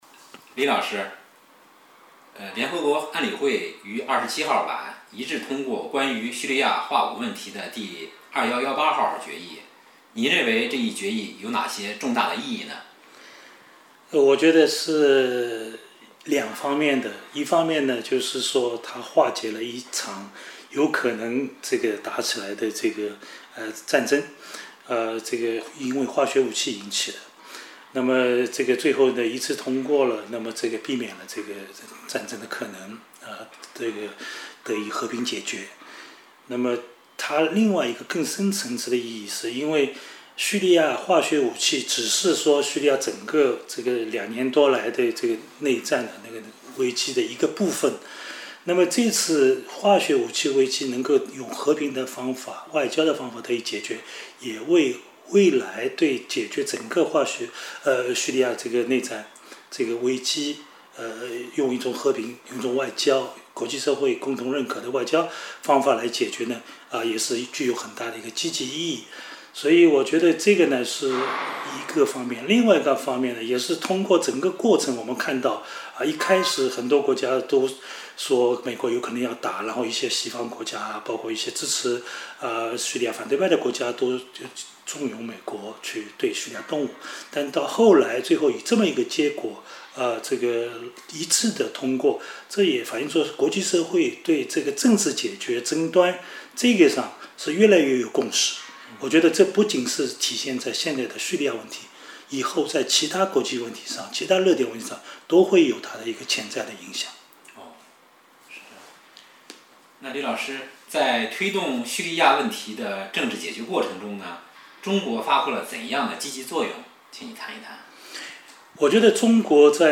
延伸阅读 论文 时评 专著 研究报告 专家访谈:叙利亚危机可能峰回路转 2013-09-29 9月27日，联合国安理会一致通过2118号决议，为政治解决叙利亚问题奠定了基础。